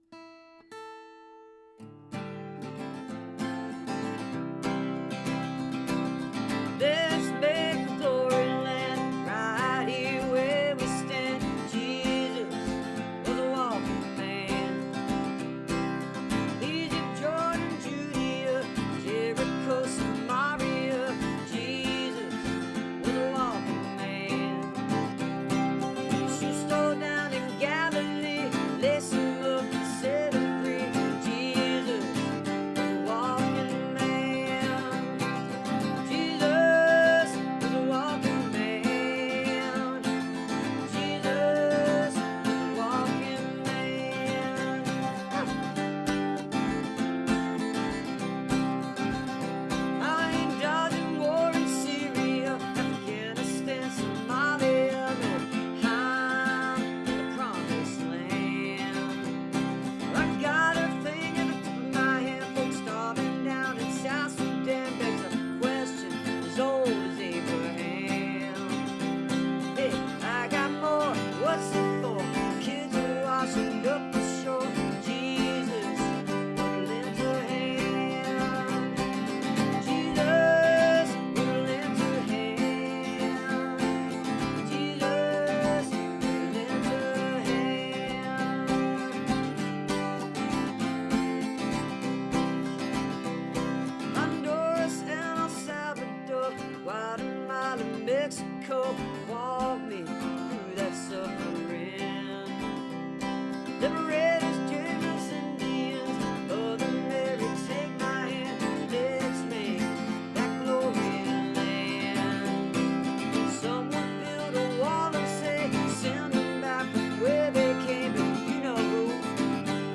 (audio from a portion of the program captured from webcast)